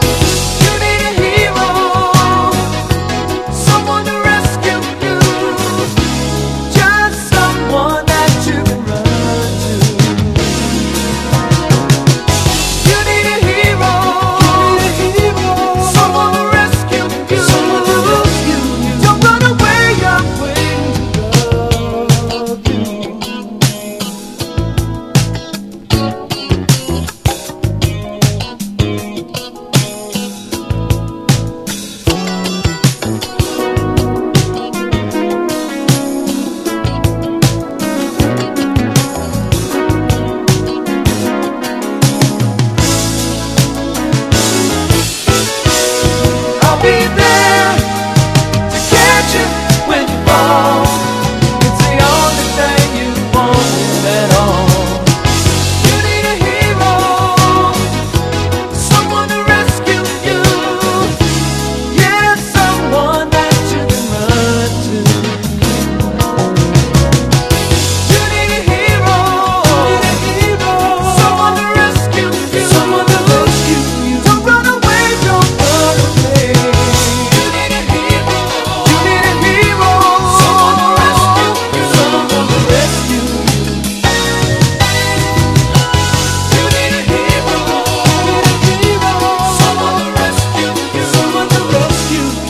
ROCK / S.S.W./A.O.R. / COUNTRY / 60'S SOUL / SWAMP / VOCAL
ナッシュヴィル録音！